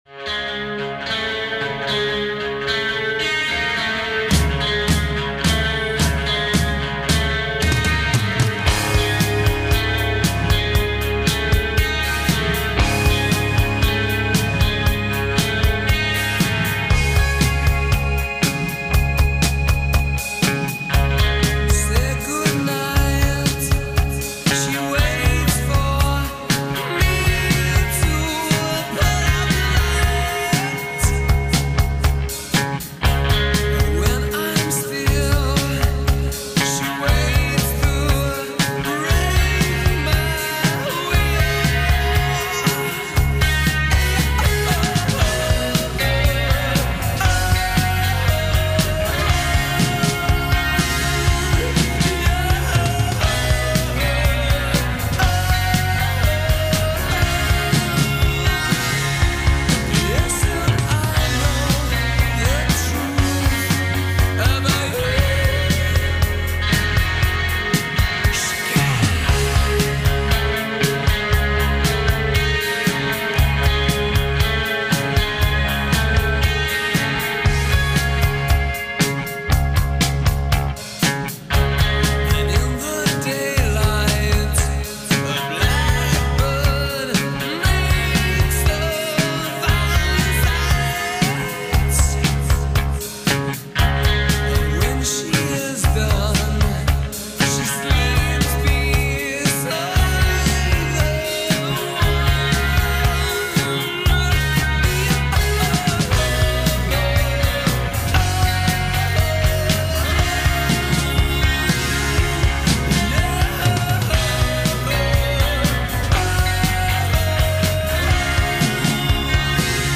Live In Session at The BBC